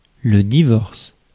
Ääntäminen
Ääntäminen France: IPA: [lə di.vɔʁs] Tuntematon aksentti: IPA: /di.vɔʁs/ Haettu sana löytyi näillä lähdekielillä: ranska Käännös Substantiivit 1. бракоразвод Muut/tuntemattomat 2. развод {m} Suku: m .